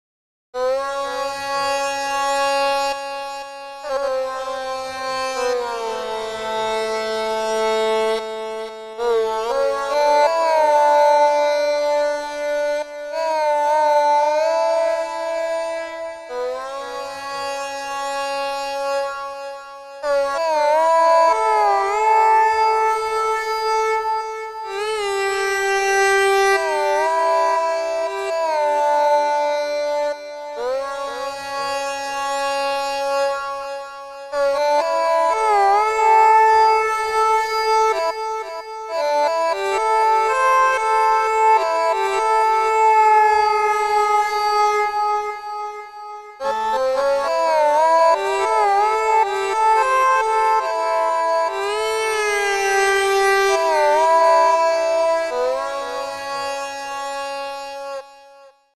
The esraj is a bowed stringed instrument used in Northeast and central India.
Ornamentation is achieved by sliding up and down the fingerboard.
AUDIO CLIP: Esraj
israj.mp3